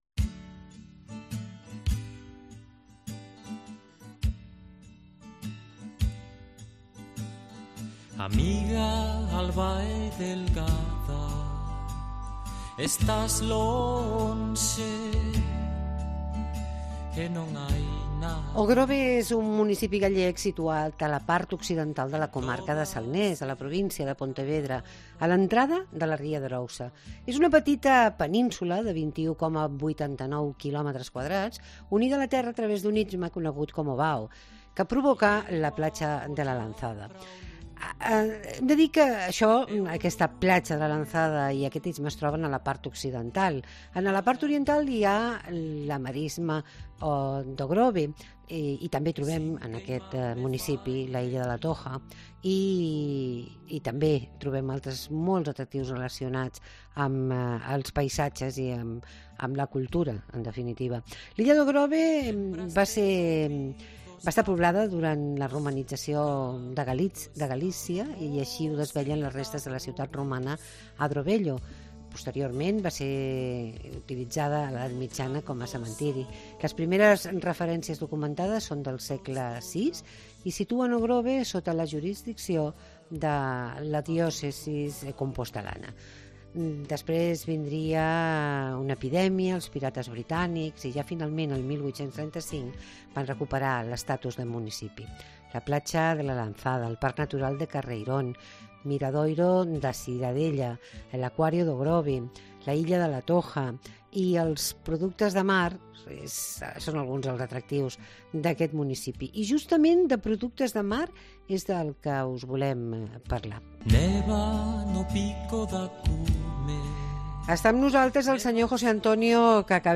Entrevista al alcalde de O'Grove, José Antonio Cacabelos